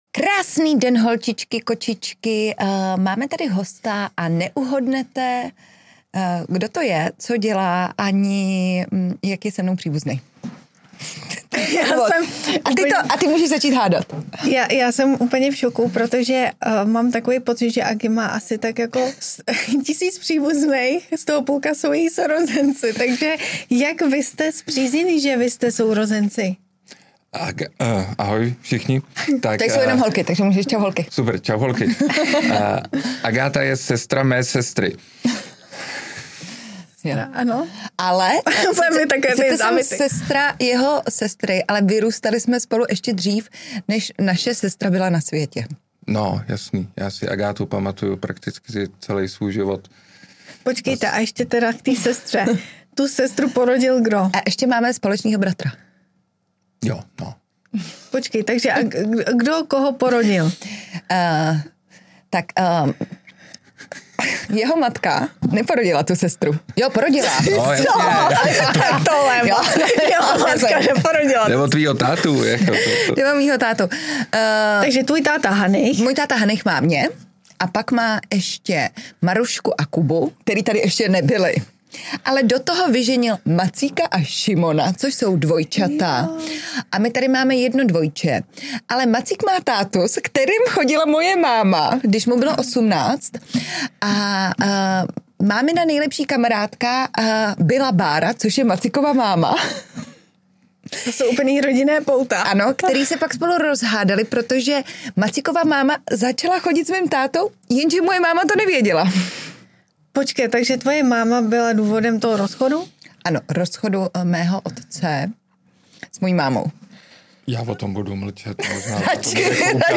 Poslechněte si povídání velice zajímavého chlapa a performera.